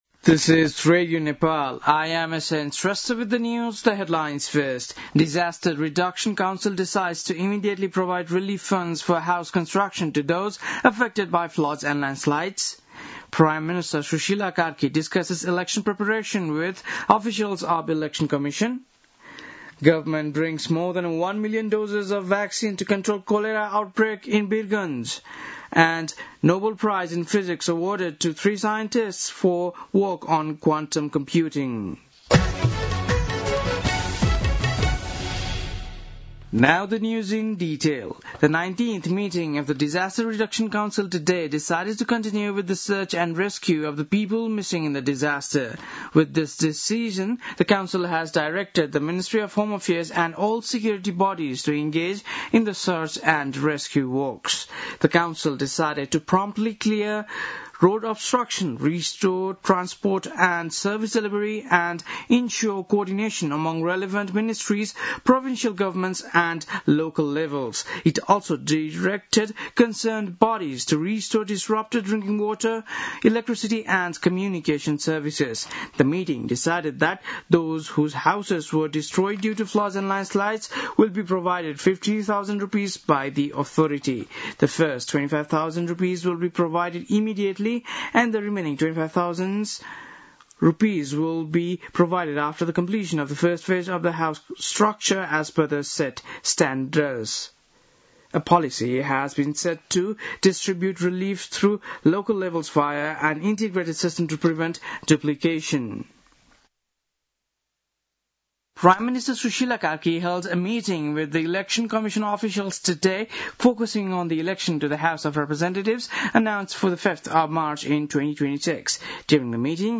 बेलुकी ८ बजेको अङ्ग्रेजी समाचार : २१ असोज , २०८२
8-pm-english-news-6-21.mp3